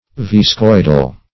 \Vis*coid"al\